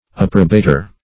approbator - definition of approbator - synonyms, pronunciation, spelling from Free Dictionary Search Result for " approbator" : The Collaborative International Dictionary of English v.0.48: Approbator \Ap"pro*ba`tor\, n. [L.] One who approves.